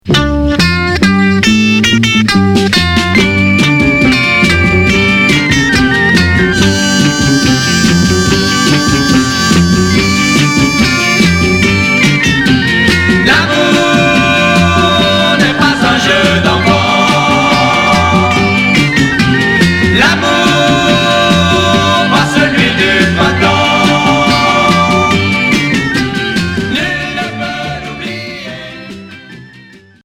Beat